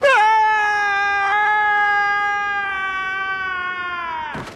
Peter Griffin Ahh